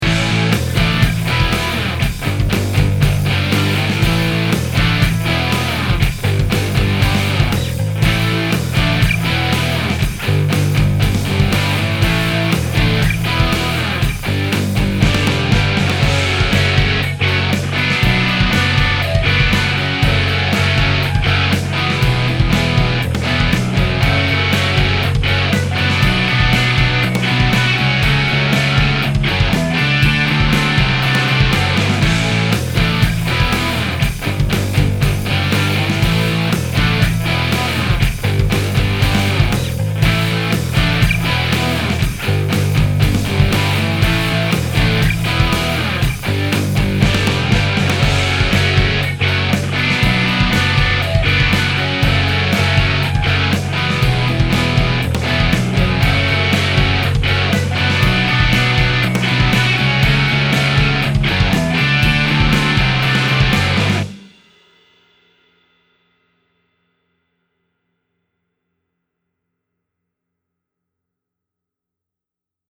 AMP 1 : non-delayed in L, 30ms delayed, detuned +10 cents in R (-2dB)
AMP 2 : non-delayed in R, 20ms delayed, detuned -5 cents in L (-2dB)
Parallel Haas + Pitch Shifting :
It’s a subtle effect, but the pitch shift thickens the sound.
5-parallel-haas-pitch_v3.mp3